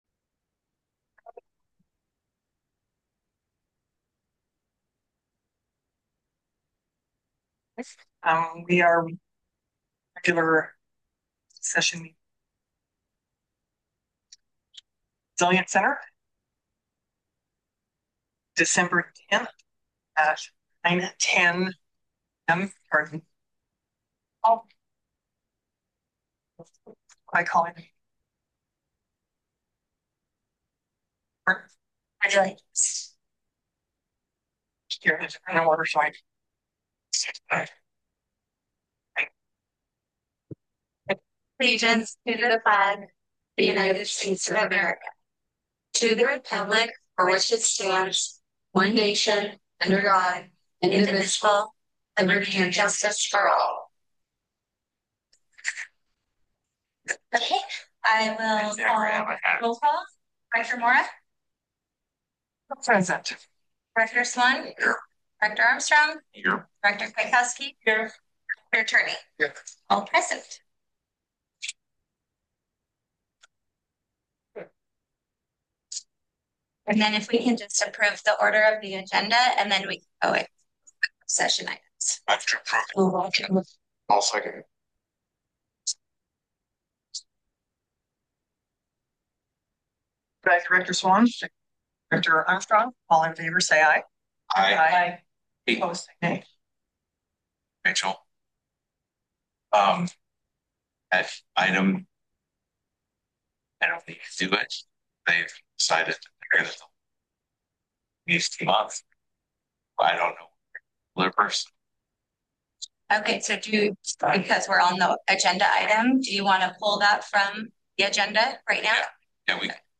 Regular Meetings are scheduled on the second Wednesday of each month at 9am in the board room located at the Groveland Community Resilience Center…
Board Meeting